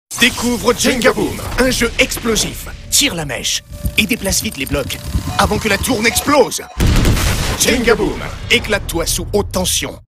Prestation dynamique et punchy pour Jenga Boom, une voix-off pleine de défi
Action, jeu et défi avec de l'enthousiasme.
Spot tv du jeu « Jenga Boom » par Maul Productions.
Avec une hauteur de voix médium grave, j’ai pu apporter une certaine gravité à l’univers du jeu, tout en restant dans une tonalité dynamique et punchy.